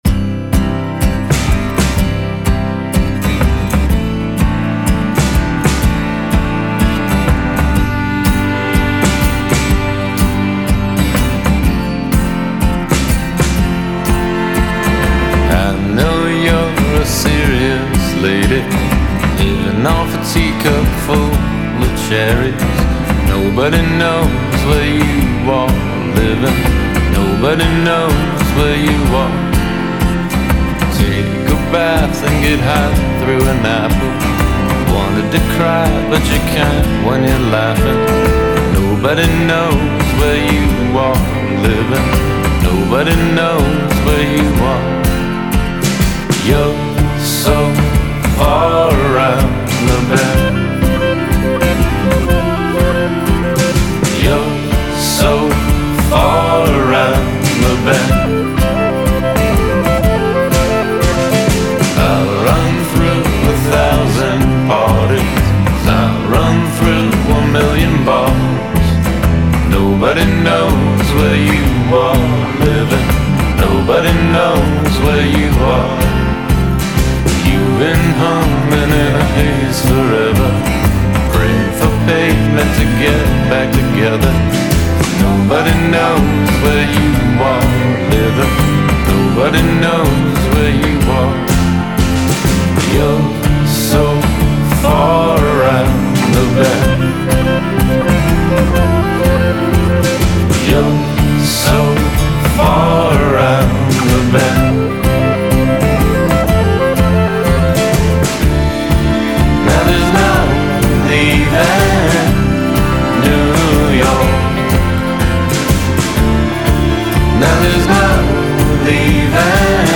most melodic and downtrodden-ly inspiring